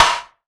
SNARE 002.wav